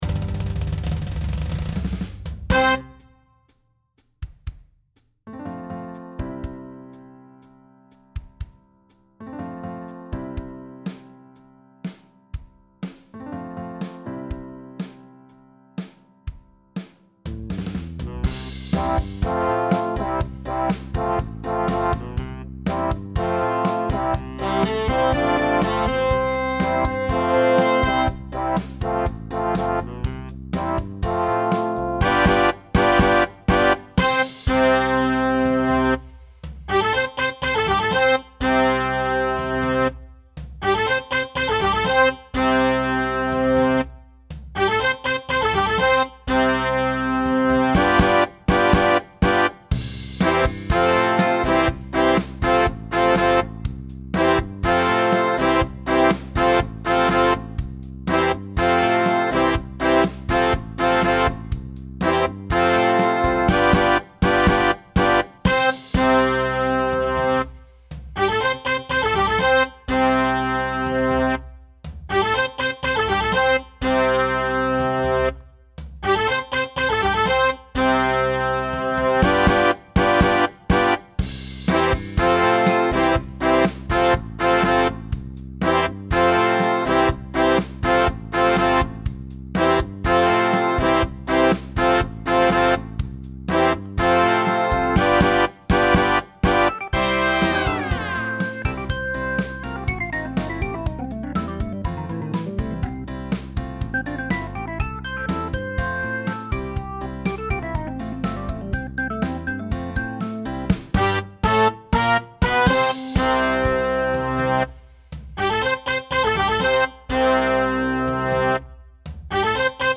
Category: Big Band Vocal